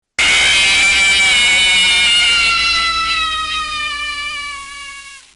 KFesIiolLUN_fnaf-1-full-jumpscare-sound.mp3